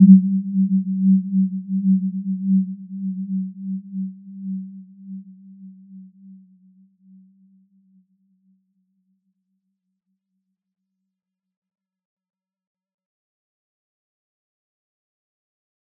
Warm-Bounce-G3-f.wav